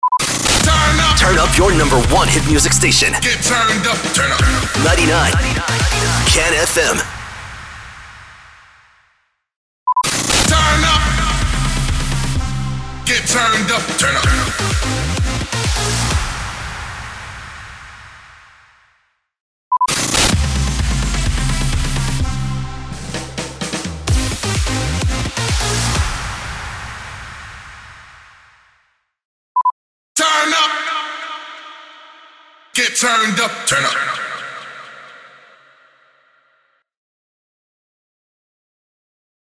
490 – SWEEPER – TURN UP